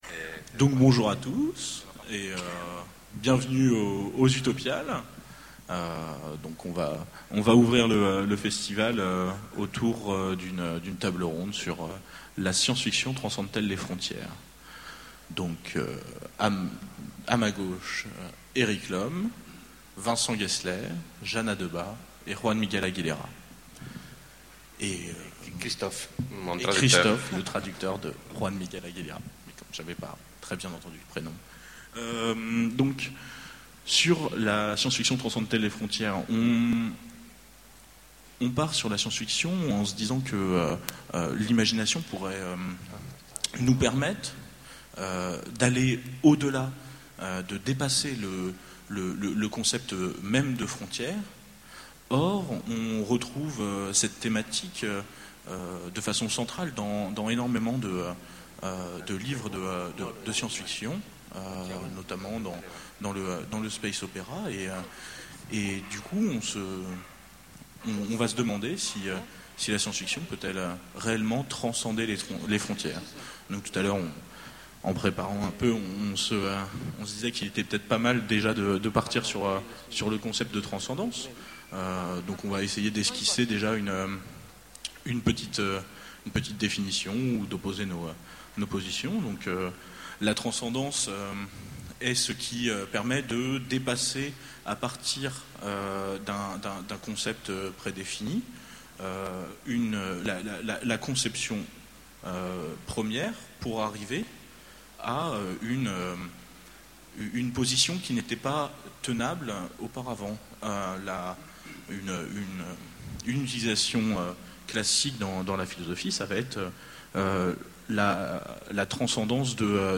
Voici l'enregistrement de la conférence " La SF transcende-t-elle les frontières ? " aux Utopiales 2010. Même si la science-fiction n’a de cesse de repousser les frontières des possibles, peut-elle les transcender ?